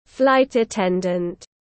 Tiếp viên hàng không tiếng anh gọi là flight attendant, phiên âm tiếng anh đọc là /ˈflaɪt əˌten.dənt/.
Flight attendant /ˈflaɪt əˌten.dənt/
Flight-attendant.mp3